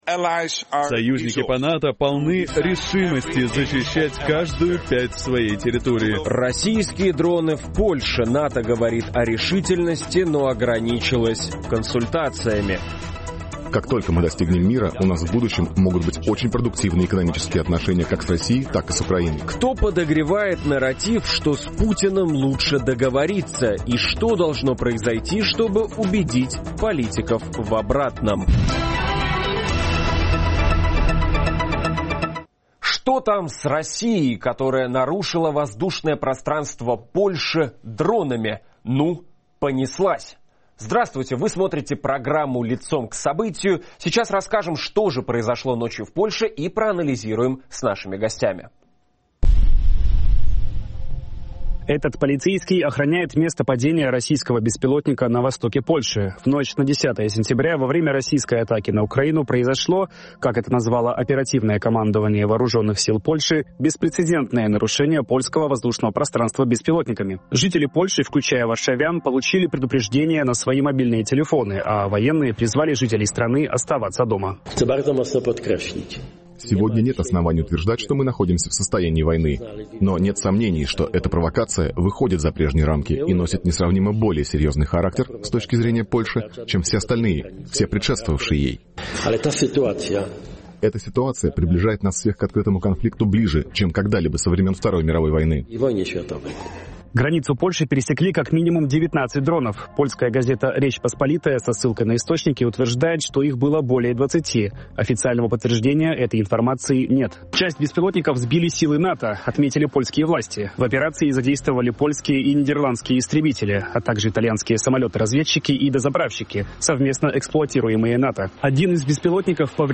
Российские дроны в Польше - ошибка или план? Чем страны ЕС могут ответить Москве? Обсуждаем в программе "Лицом к событию" с военным экспертом